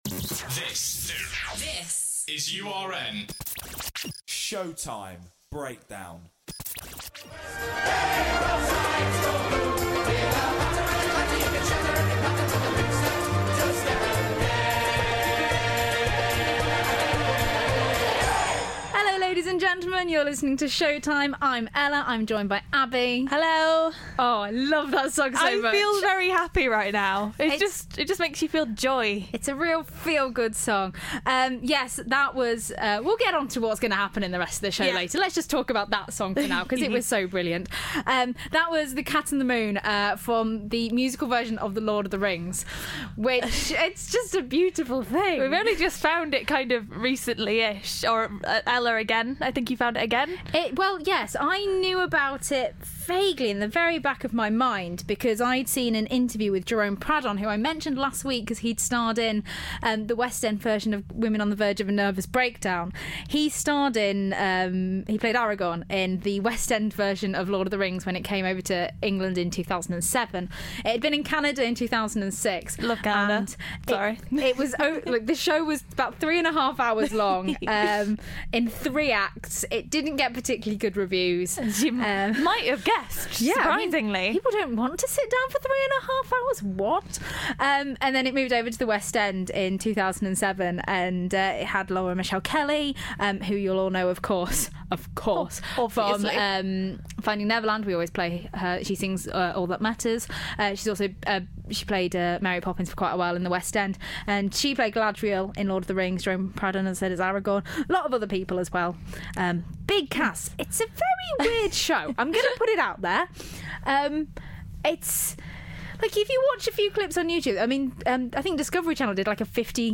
Everybody's favourite duo are back for another week of musical madness!